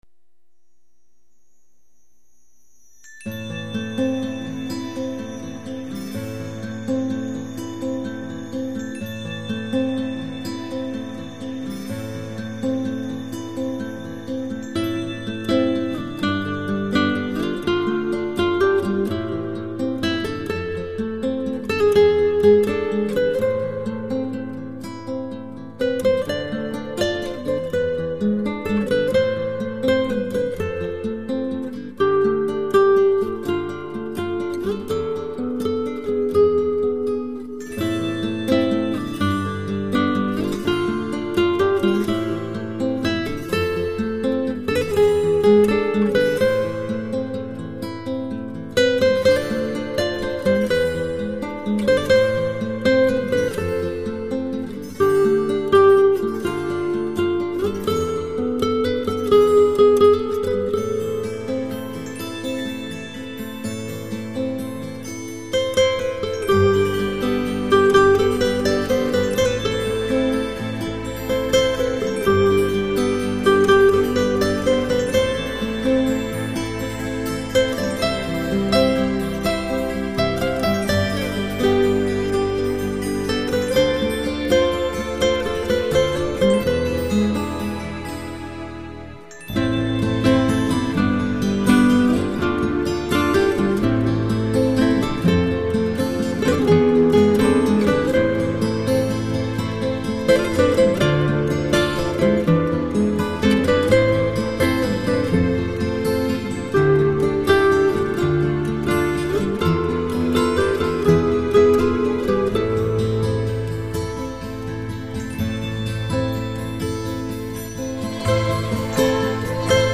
Чистый релакс.